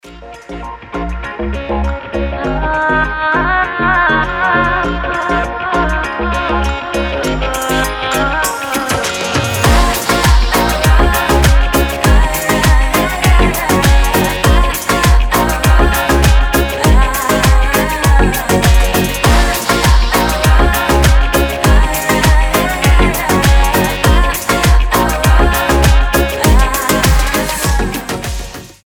поп , танцевальные , легкие
татарские